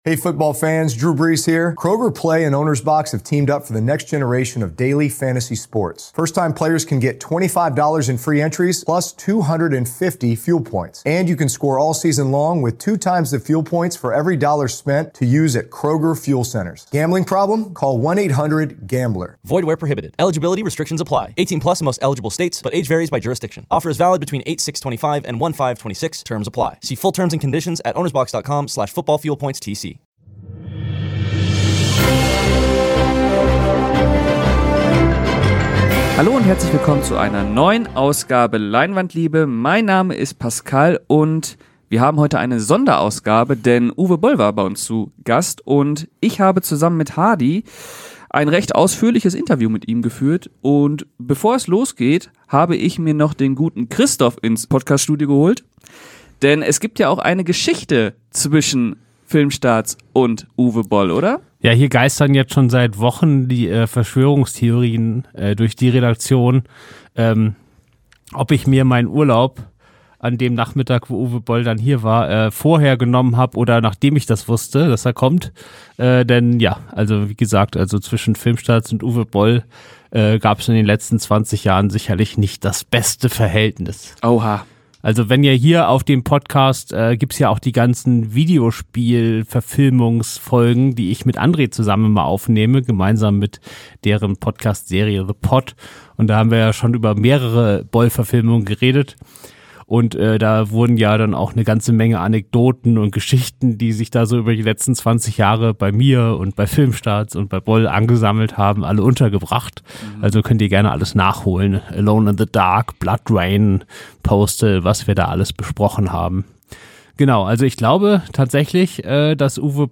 Wir hatten wieder Promi-Besuch, denn Regisseur Uwe Boll war zu Gast im Berliner FILMSTARTS-Büro!